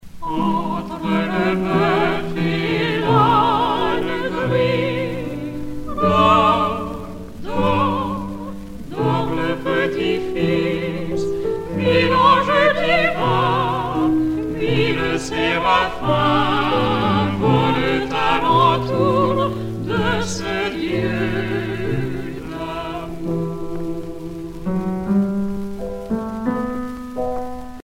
Noël
Genre strophique
Pièce musicale éditée